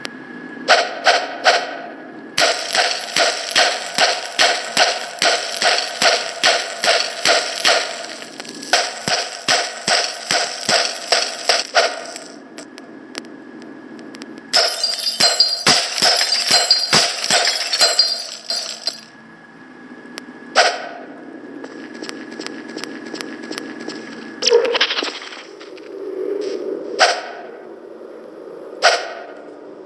Crowbar Swoosh